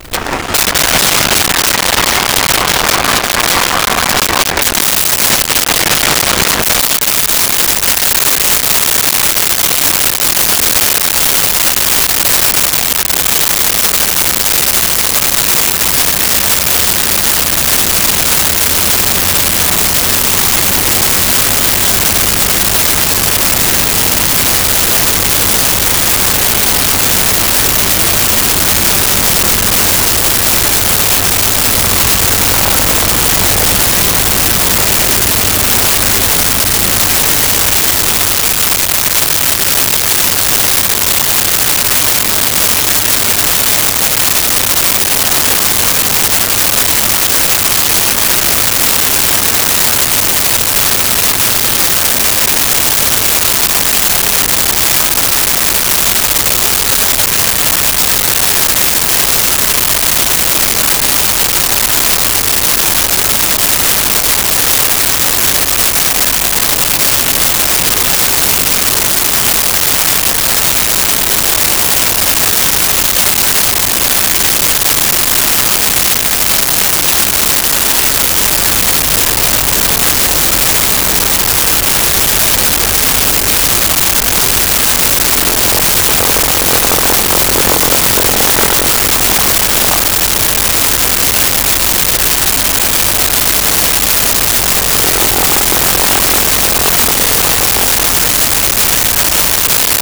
Prop Plane Start Idle
Prop Plane Start Idle.wav